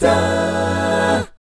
1-F#MI7 AA-L.wav